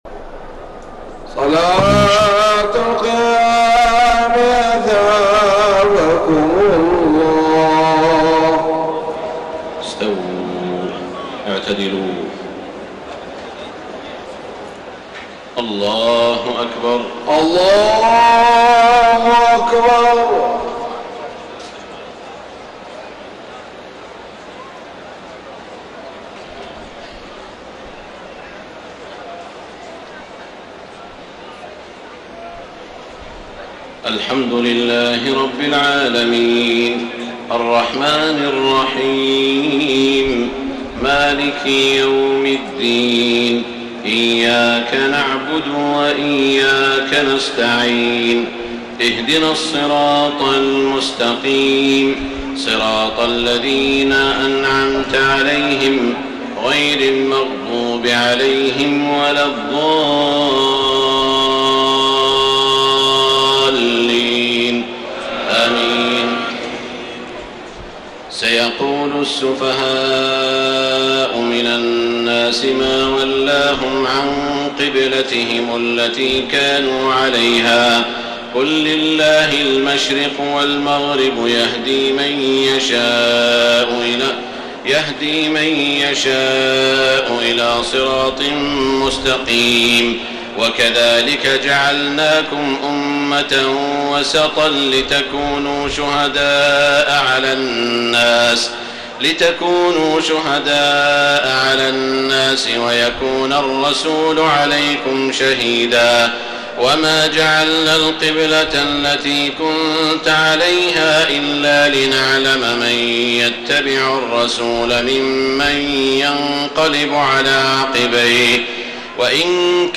تهجد ليلة 22 رمضان 1433هـ من سورة البقرة (142-218) Tahajjud 22 st night Ramadan 1433H from Surah Al-Baqara > تراويح الحرم المكي عام 1433 🕋 > التراويح - تلاوات الحرمين